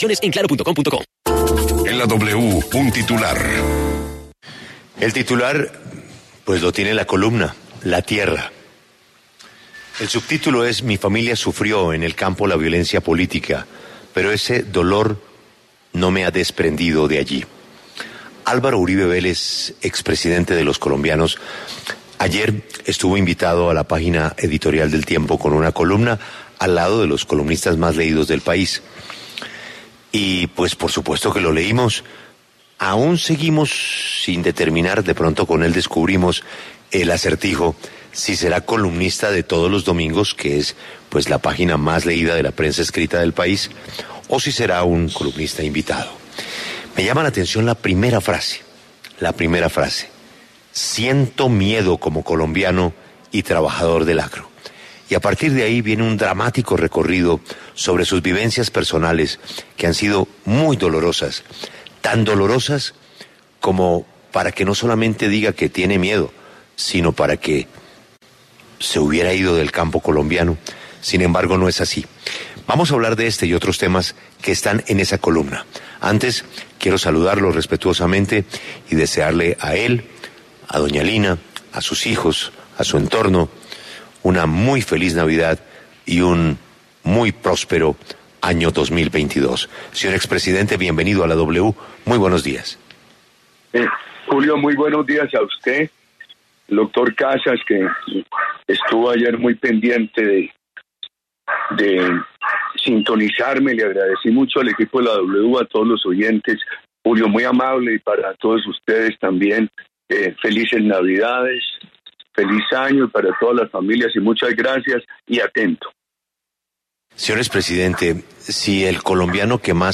En diálogo con La W, el expresidente Álvaro Uribe conversó acerca de los temas que le preocupan del país y que plasmó en su columna del diario El Tiempo el pasado fin de semana.